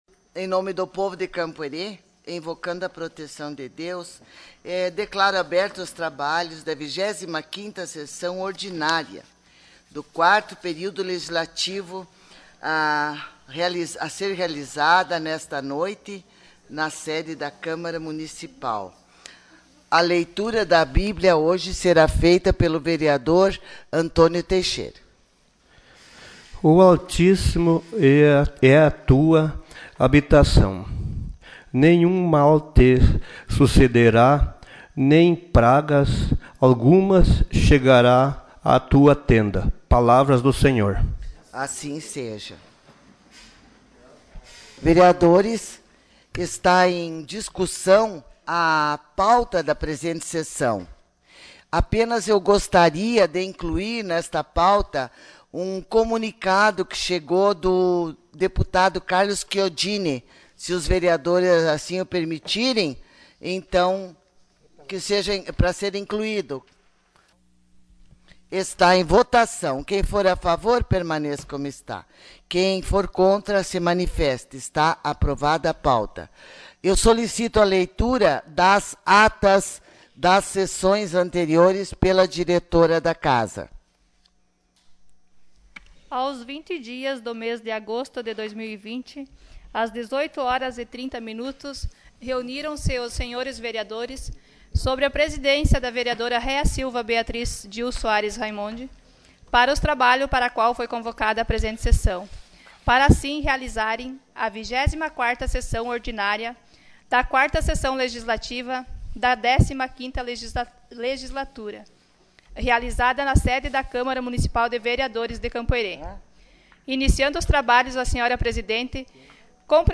Sessão Ordinária dia 27 de agosto de 2020